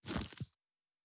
收起背包.wav